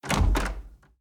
Door Close 2.ogg